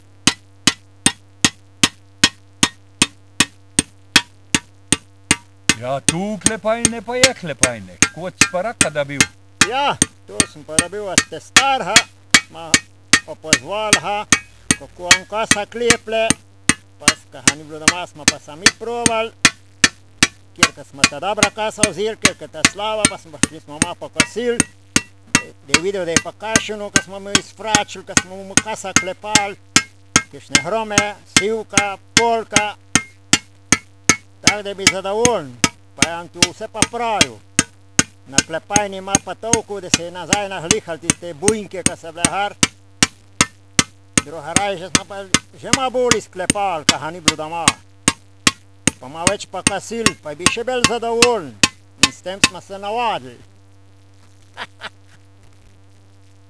KLEPAJNE
(Ob gradnji spletišča so bili zvočnni zapisi v dialektu še redkost)
klepajne2.wav